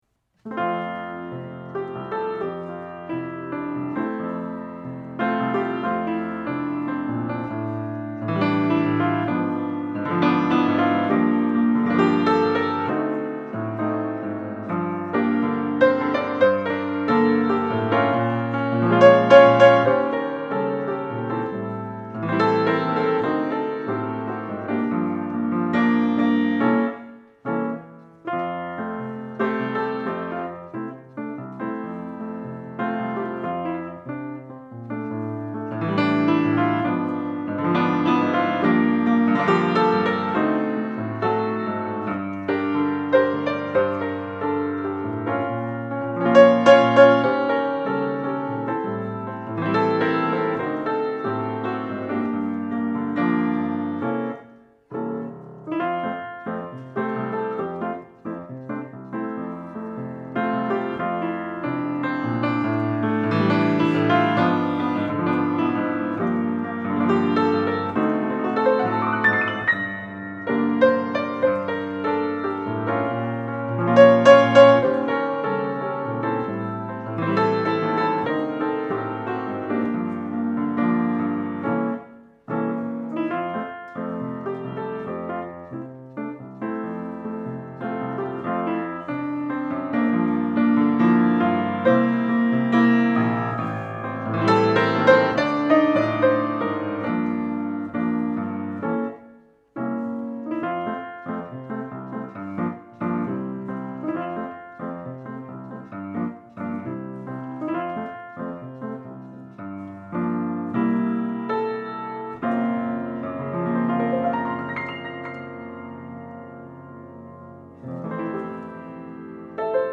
Easy Listening
Solo Piano Arrangement
Cocktail Music
Piano Jazz